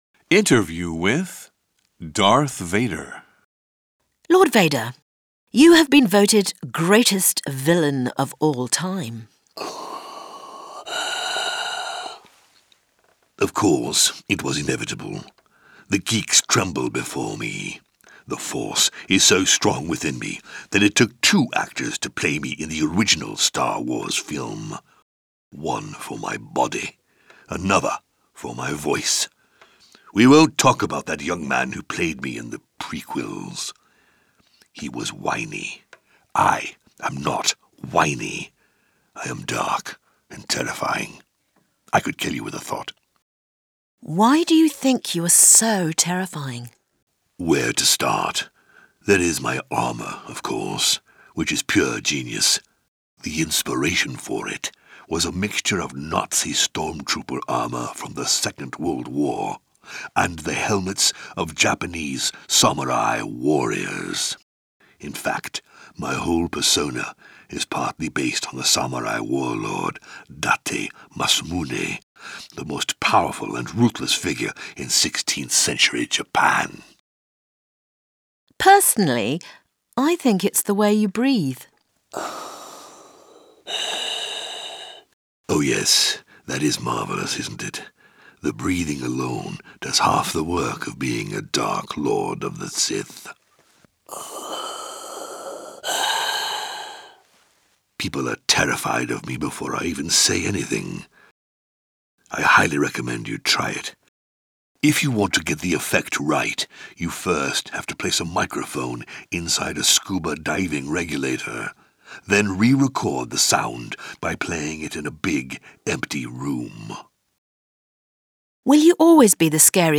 EN ATTENTE TDYN0349_FULL_INTERVIEW WITH_T004_Darth Vader